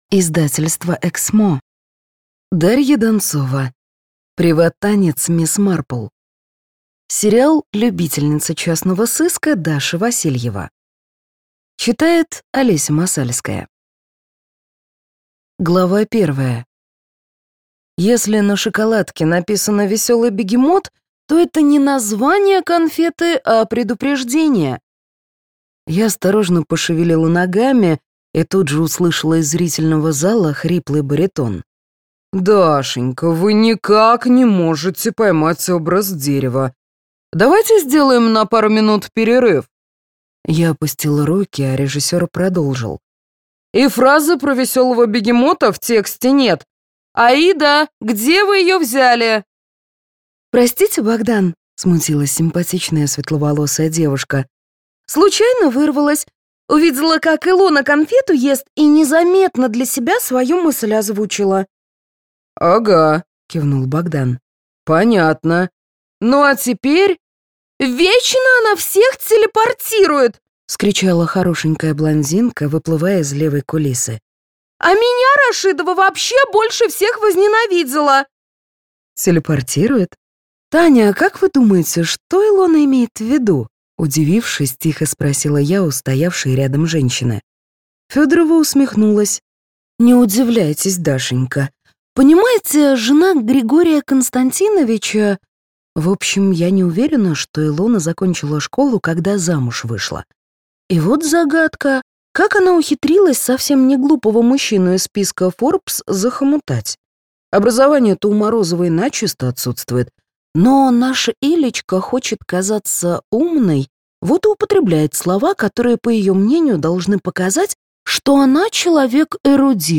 Аудиокнига Приват-танец мисс Марпл - купить, скачать и слушать онлайн | КнигоПоиск